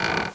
od_creak.wav